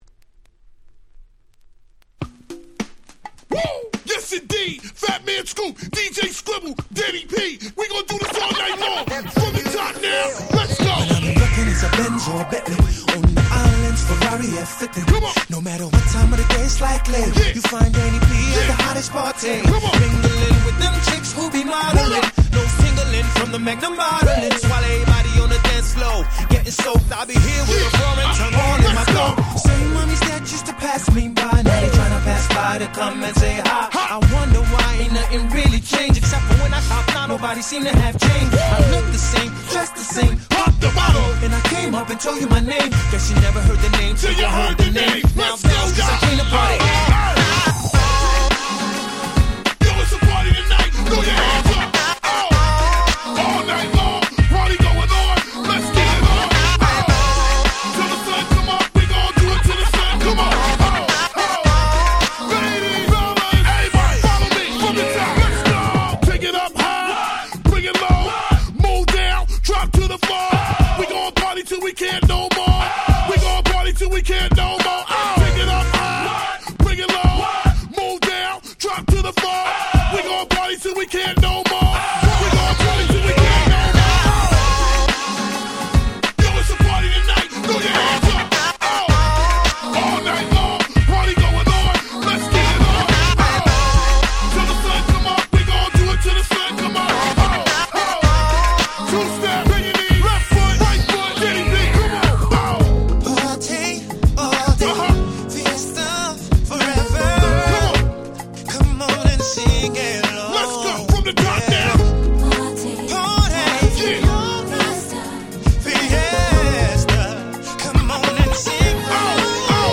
04' Nice R&B / Party Tracks !!